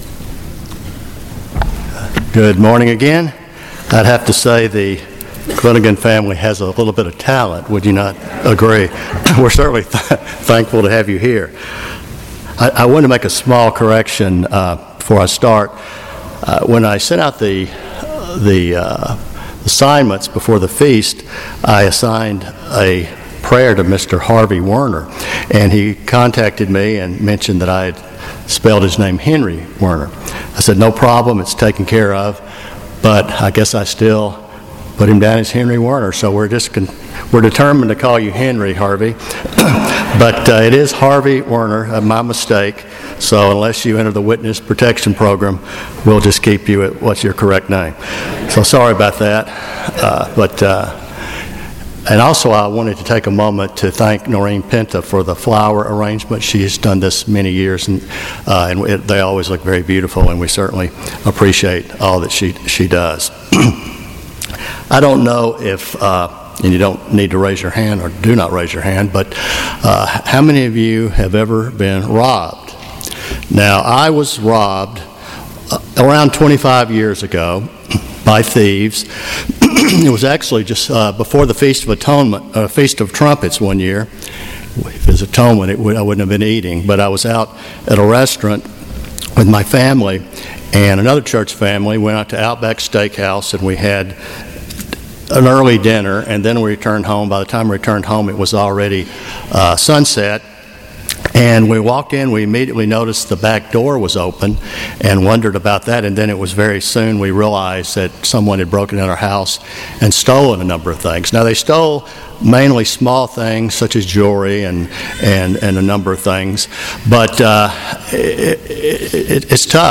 Sermon
2017 Feast of Tabernacles - Jekyll Island, Georgia